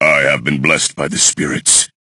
bo_lead_vo_03.ogg